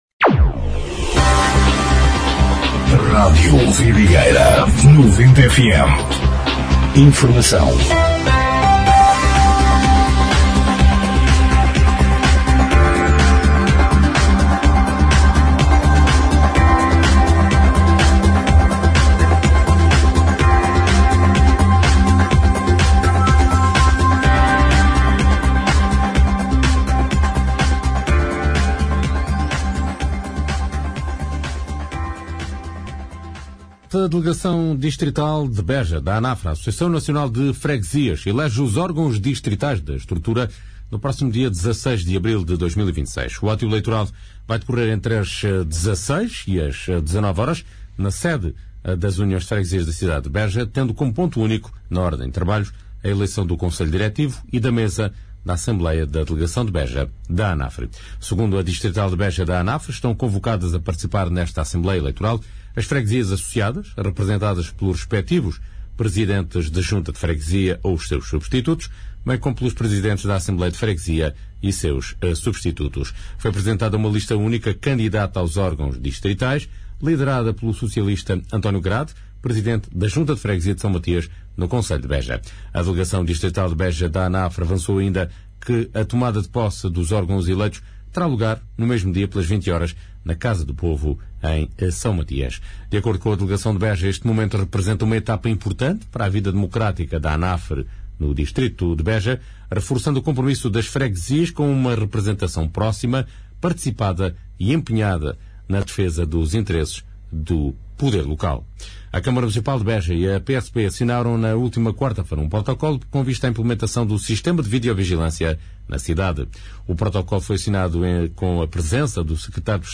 Noticiário 10/04/2026